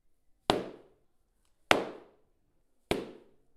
Golpes con planchas de polietileno
Golpes generados al chocar dos planchas de polietileno.